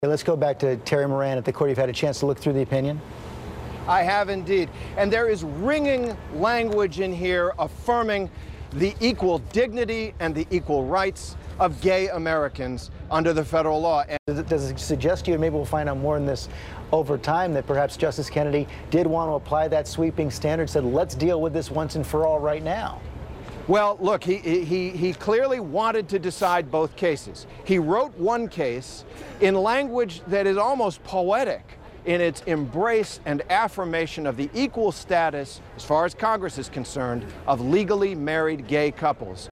During live coverage, Moran and other journalists kept cutting to California, touting the cheering and celebrations there.